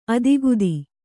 ♪ adigudi